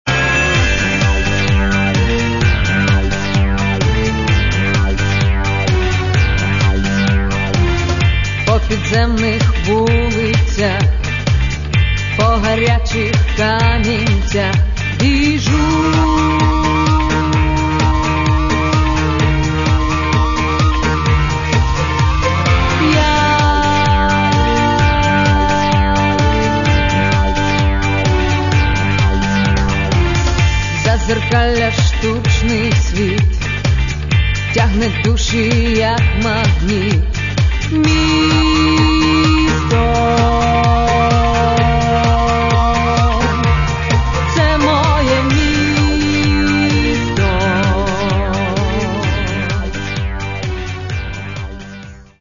Каталог -> Поп (Легка) -> Лірична